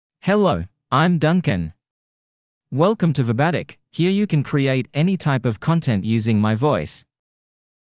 MaleEnglish (Australia)
Duncan is a male AI voice for English (Australia).
Voice sample
Male
Duncan delivers clear pronunciation with authentic Australia English intonation, making your content sound professionally produced.